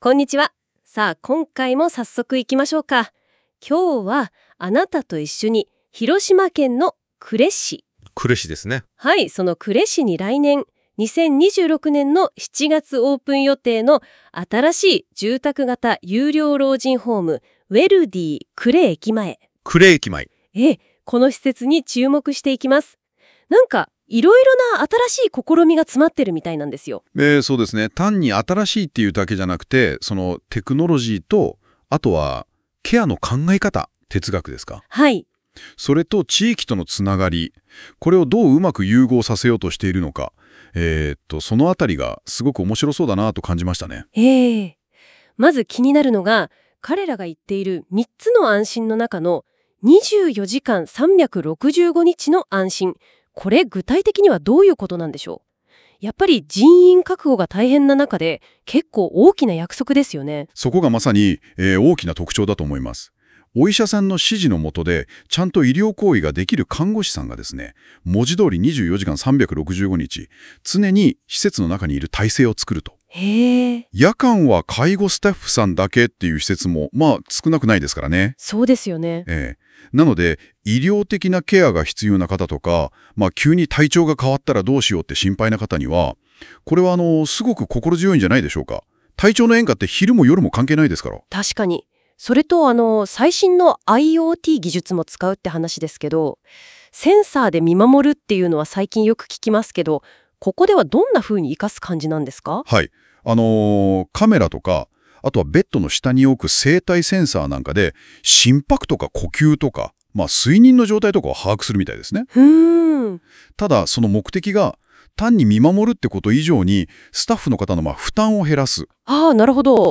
IoT技術について詳しく知る ナーシングホーム ウェルディ呉駅前を音声番組で知る ナーシングホーム ウェルディ呉駅前の特徴を、生成AIにて音楽番組風にまとめています。